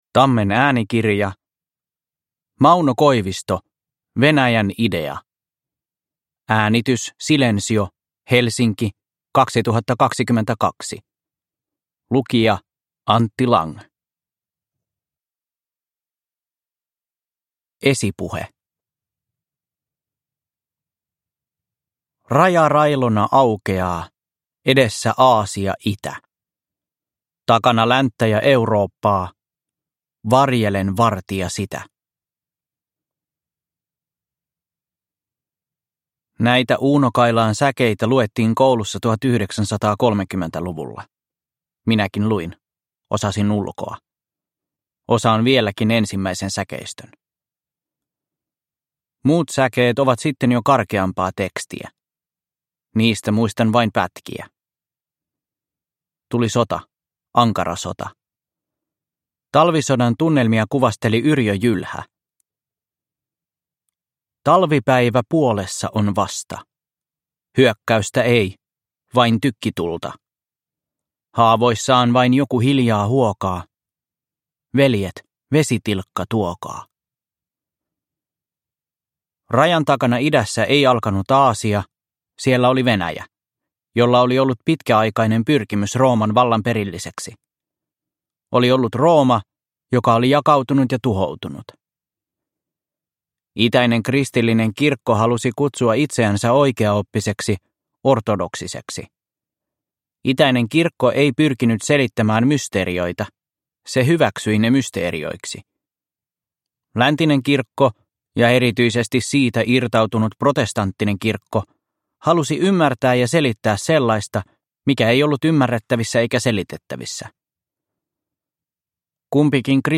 Venäjän idea – Ljudbok – Laddas ner